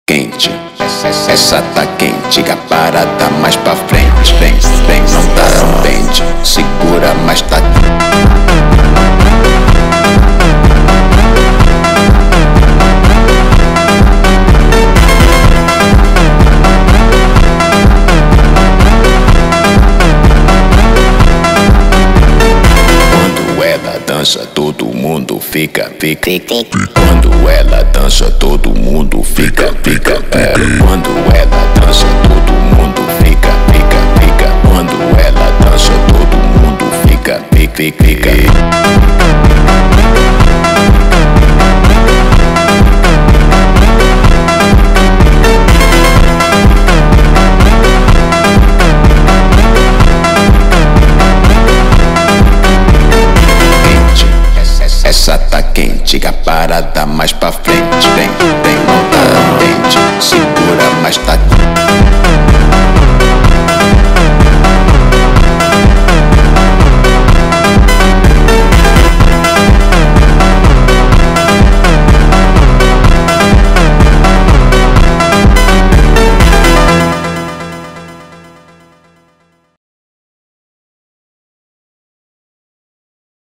فانک